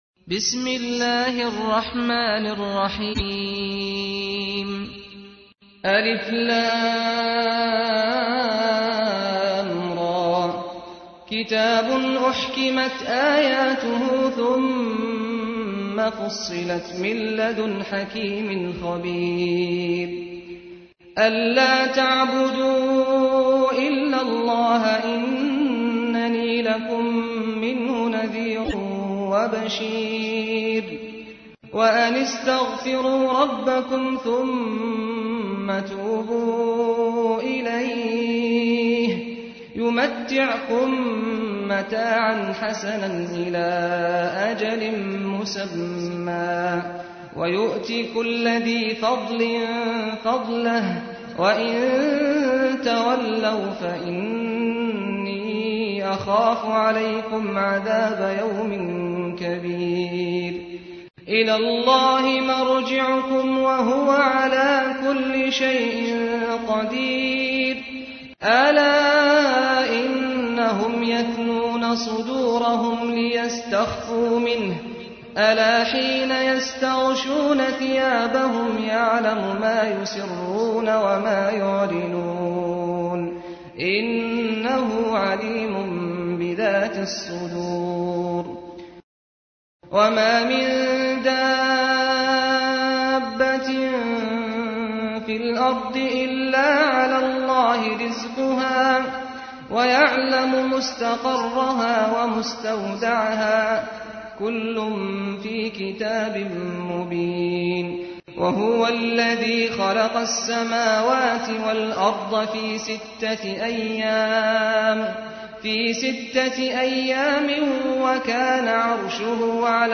تحميل : 11. سورة هود / القارئ سعد الغامدي / القرآن الكريم / موقع يا حسين